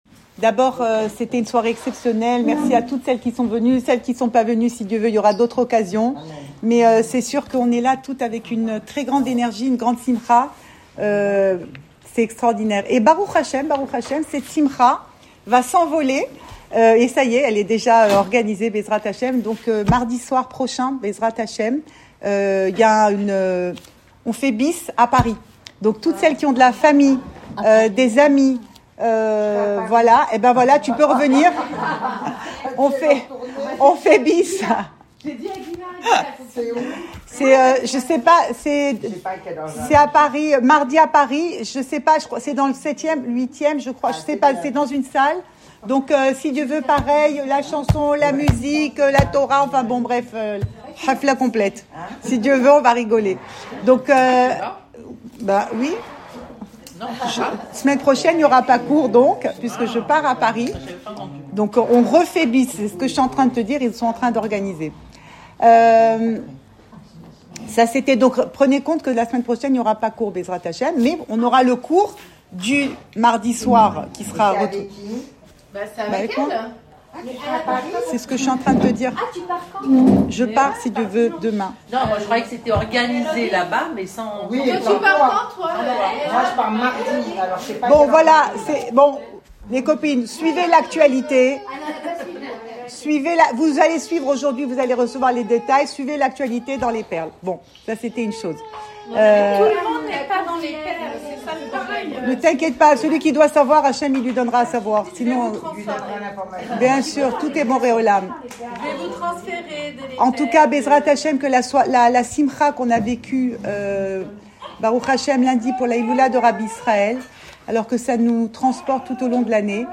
Cours audio Emouna Le coin des femmes Pensée Breslev - 20 novembre 2024 1 mai 2025 Une histoire d’amour. Enregistré à Tel Aviv